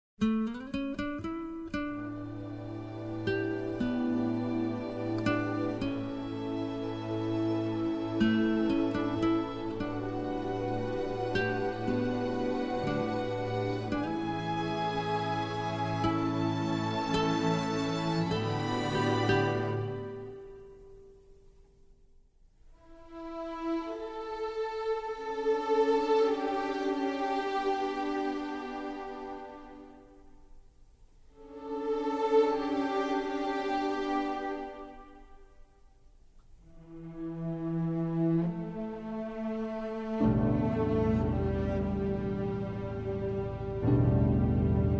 Utilizing exotic percussion and a talented flute soloist
was recorded by a non-union orchestra in Toronto